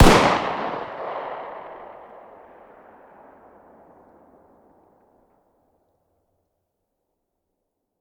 fire-dist-40sw-pistol-ext-05.ogg